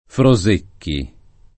Frosecchi [ fro @% kki ]